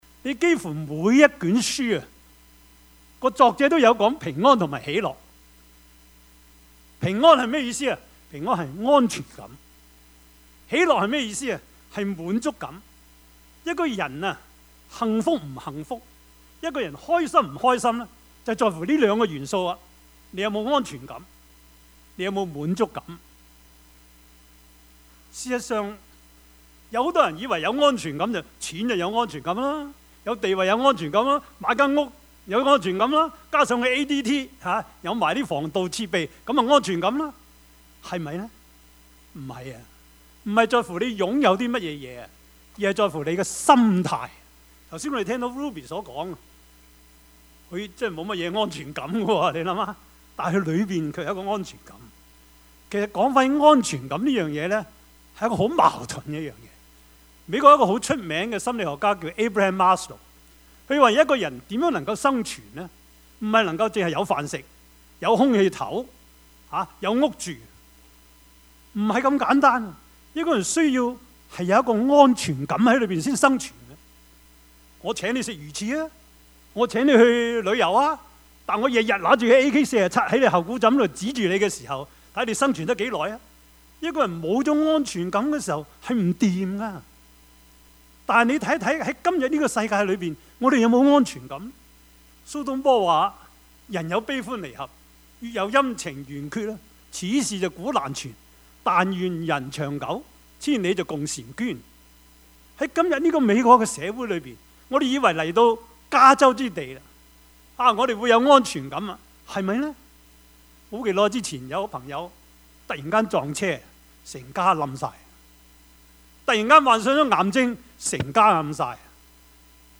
Service Type: 主日崇拜
Topics: 主日證道 « 神的揀選 蒙福的人 »